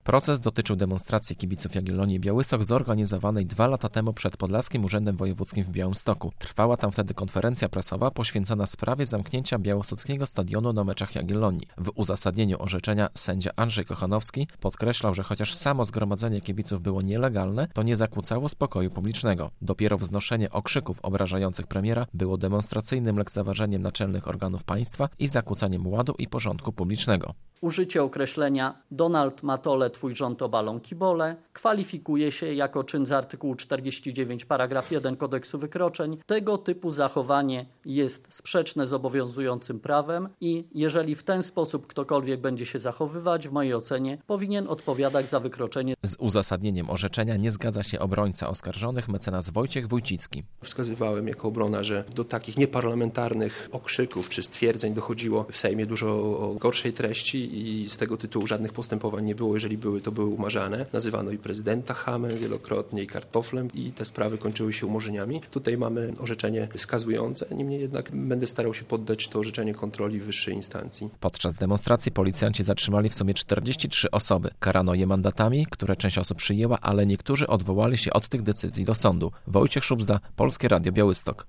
Kibice ukarani - relacja